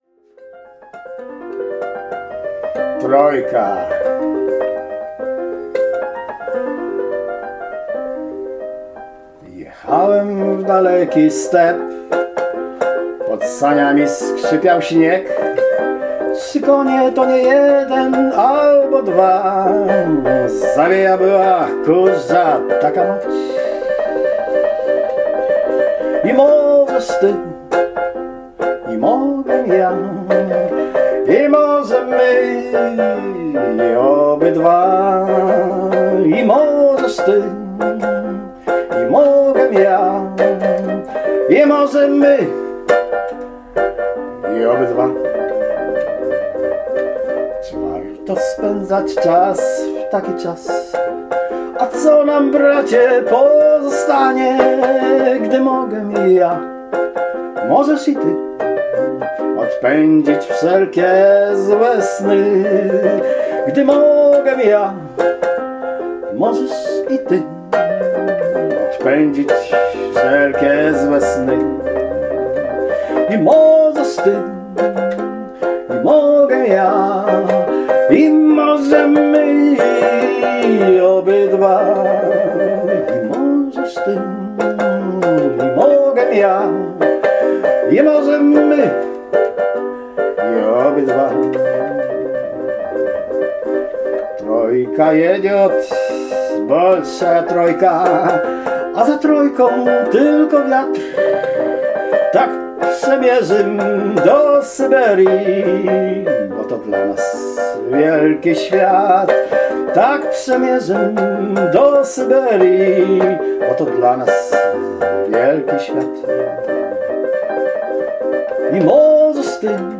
Rosyjskie romanse w nietypowym wykonaniu